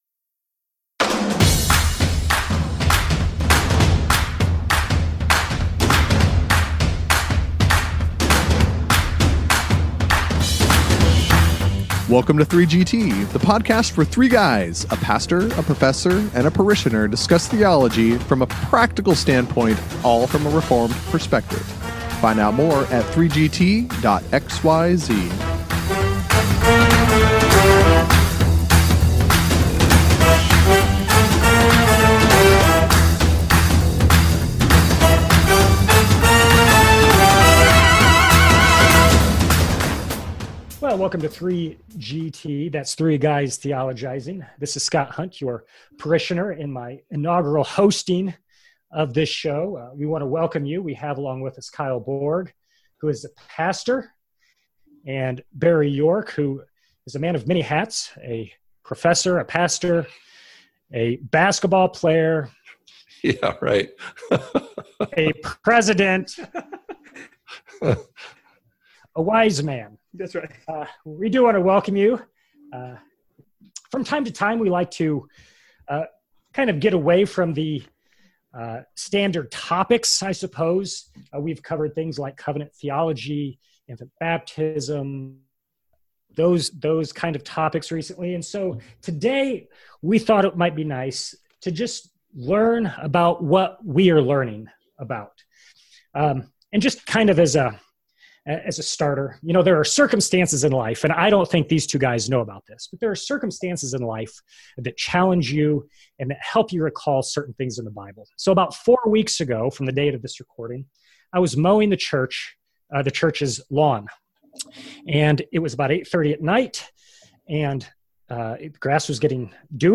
Tune in as the guys share life with you!